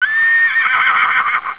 Horse Neigh 3 Sound Effect Free Download
Horse Neigh 3